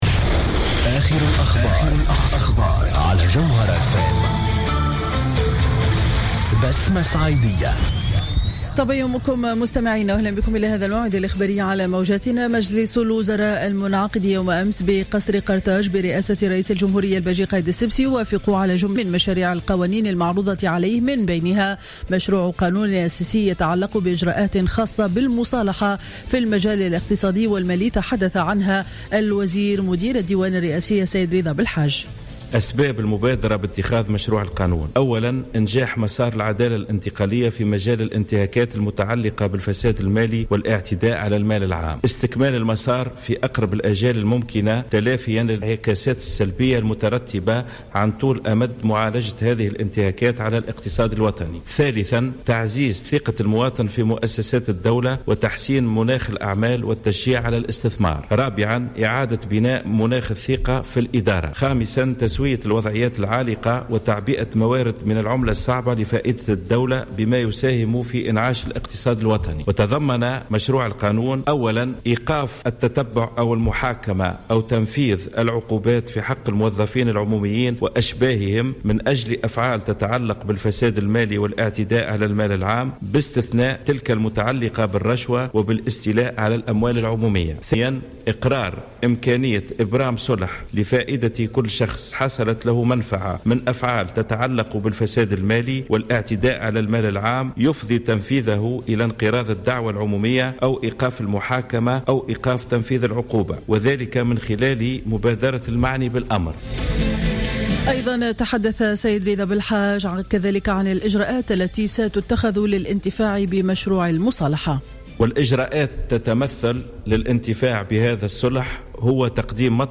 نشرة أخبار السابعة صباحا ليوم الاربعاء 15 جويلية 2015